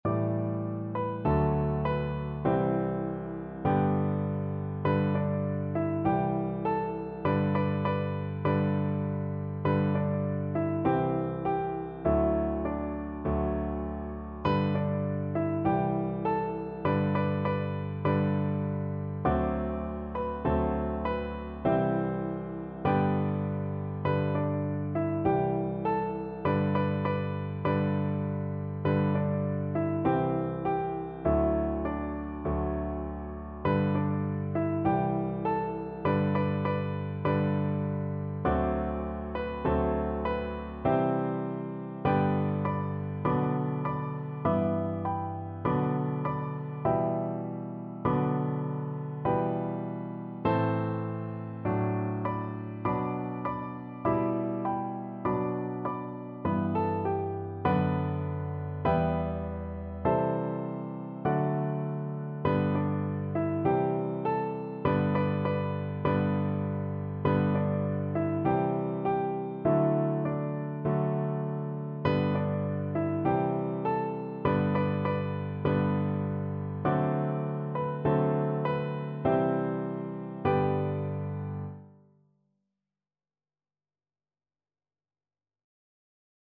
Free Sheet music for Piano Four Hands (Piano Duet)
G major (Sounding Pitch) (View more G major Music for Piano Duet )
4/4 (View more 4/4 Music)
Traditional (View more Traditional Piano Duet Music)